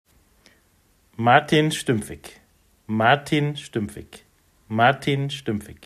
Wie spricht man eigentlich den Namen richtig aus,